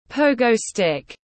Cái gậy nhảy tiếng anh gọi là pogo stick, phiên âm tiếng anh đọc là /ˈpəʊ.ɡəʊ ˌstɪk/